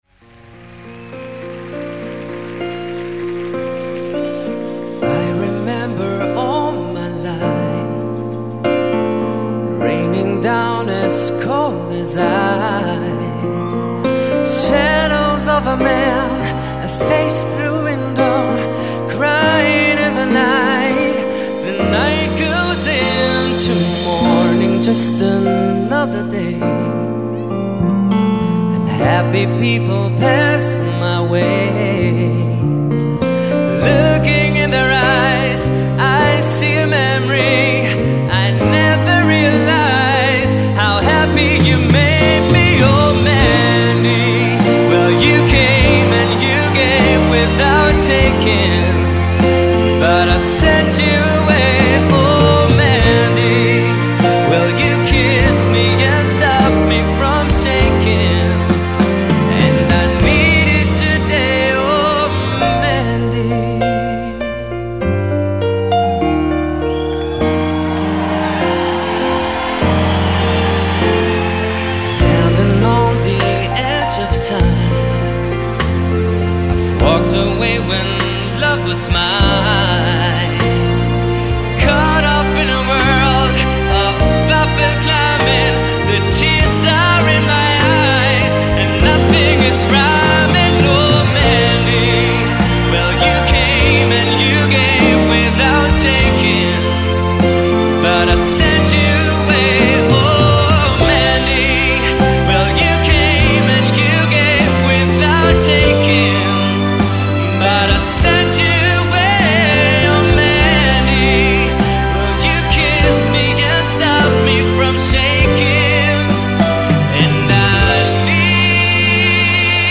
he got minutes worth of standing ovations